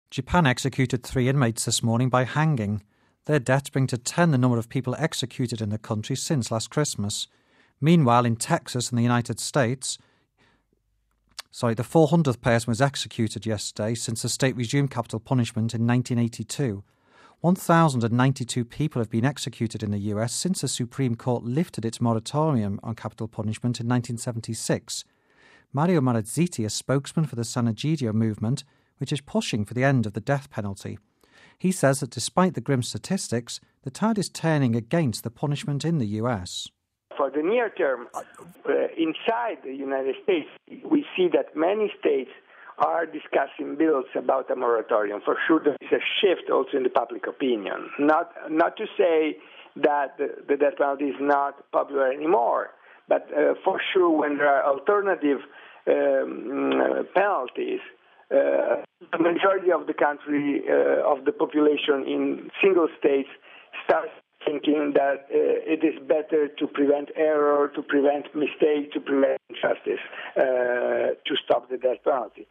Home Archivio 2007-08-23 18:49:21 The Death Penalty and Texas (23 Aug '07 - RV) Texas has executed its 400th person since the state resumed capital punishment in 1982. We have this report...